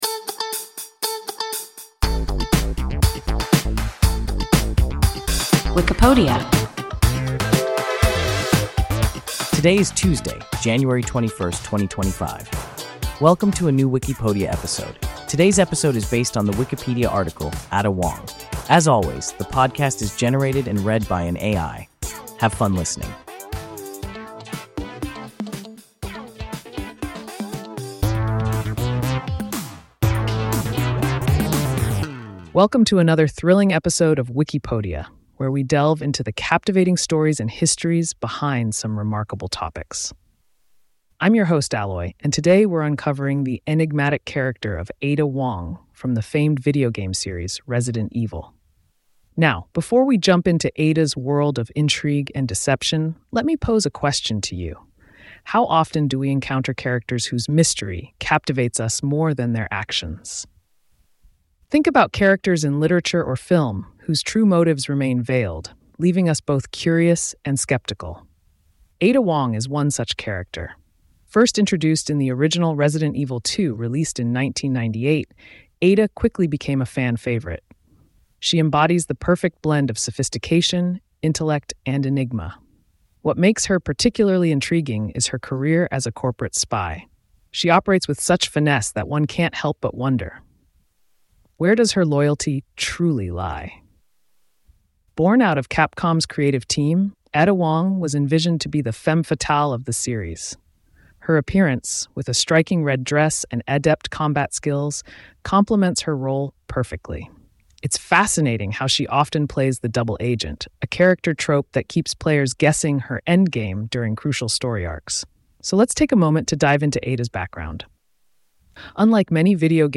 Ada Wong – WIKIPODIA – ein KI Podcast